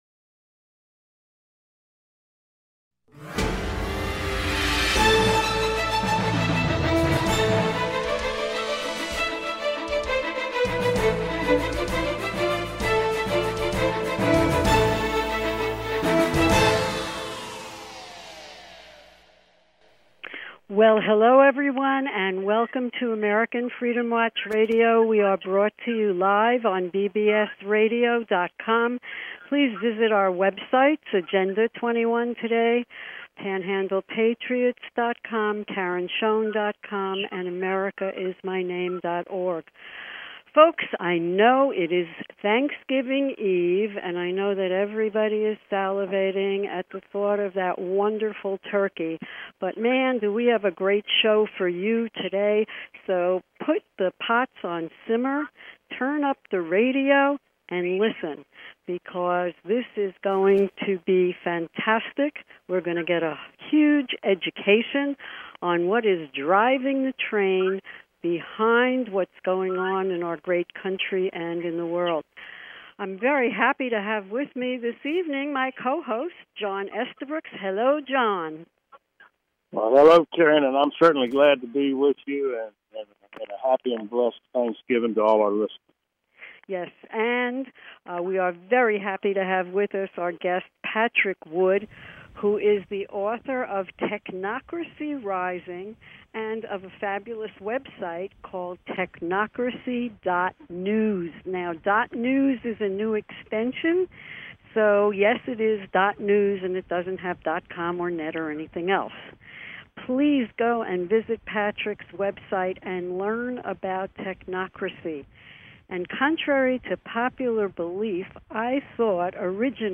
Talk Show Episode, Audio Podcast
Interview